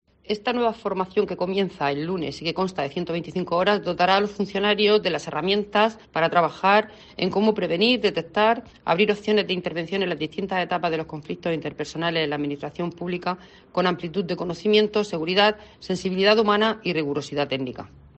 Carmen María Zamora, directora general de Función Pública